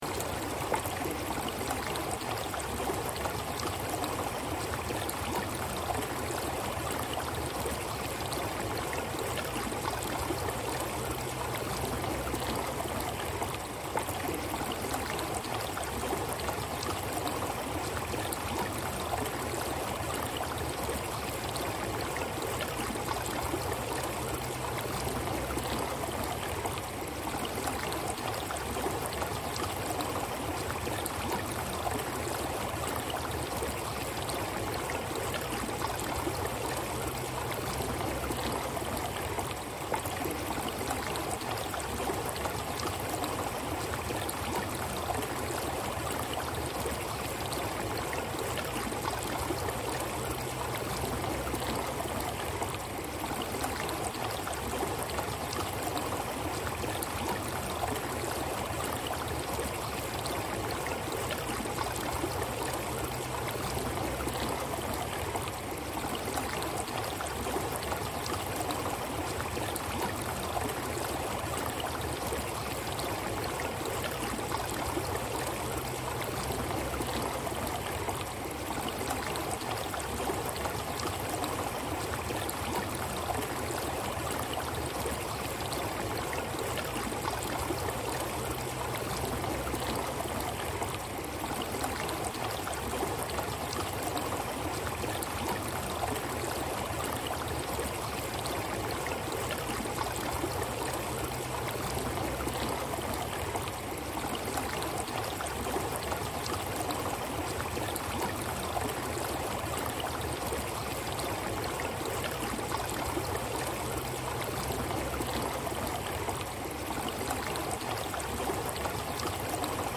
Babbling Brook Mini: Light Water Chatter for Quick Relaxation (1 Hour)
Before you press play, you should know this: all advertisements for Rain Sounds, Rain To Sleep, Rainy Day, Raining Forest, Rainy Noise are placed gently at the very beginning of each episode. That choice is intentional.